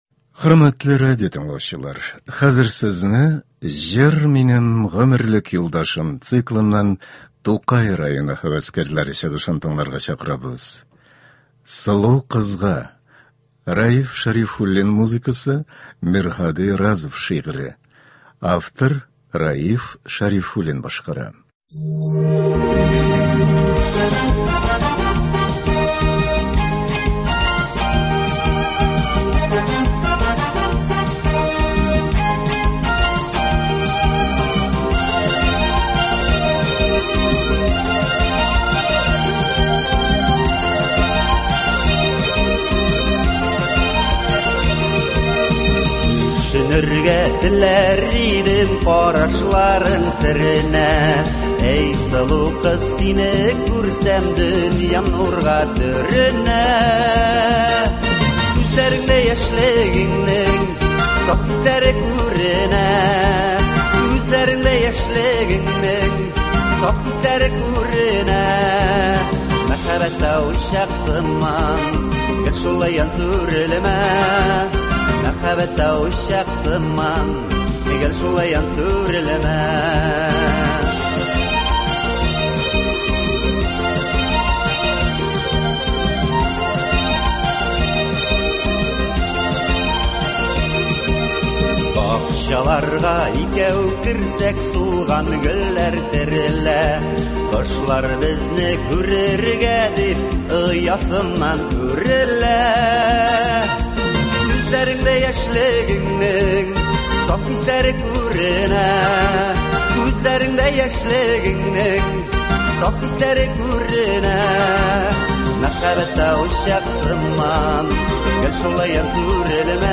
Концерт (01.02.21)